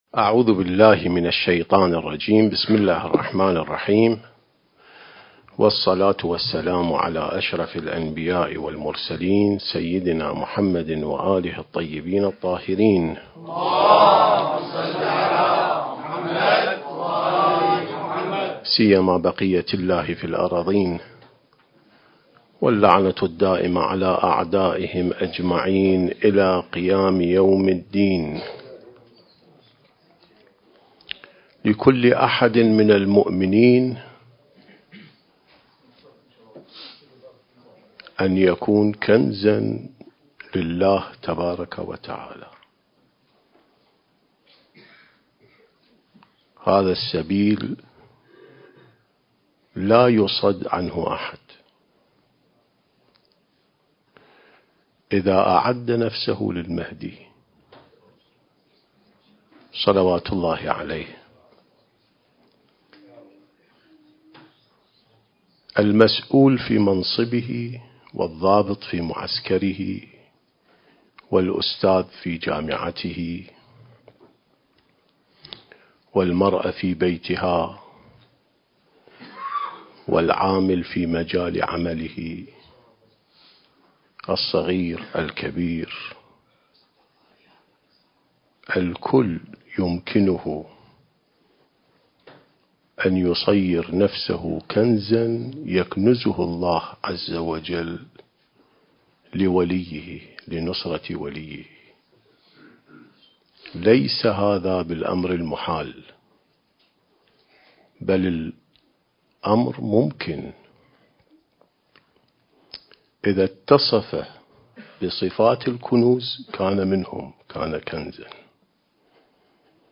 سلسة محاضرات الإعداد للمهدي (عجّل الله فرجه) (6) التاريخ: 1444 للهجرة